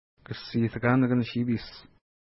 Pronunciation: kəssi:təka:nəkən-ʃi:pi:ʃ
Pronunciation